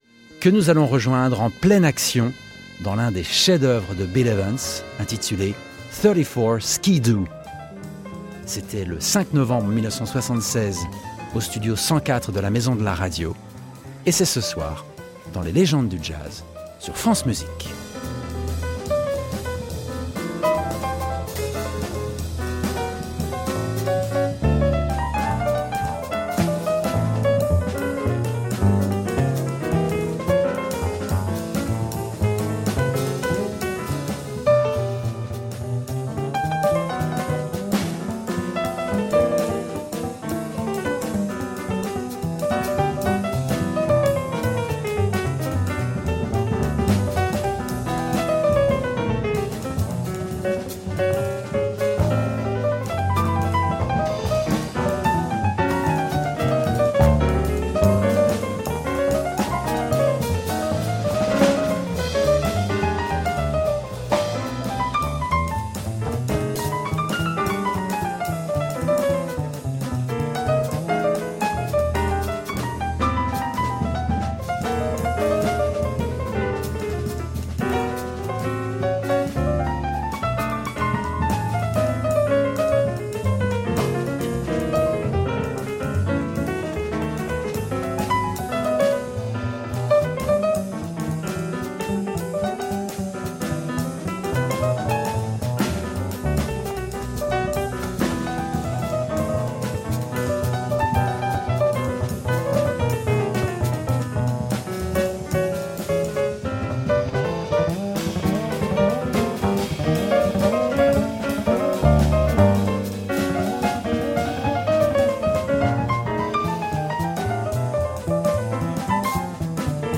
Recorded on November 5, 1976 at Maison de la Radio, Paris
Modern Jazz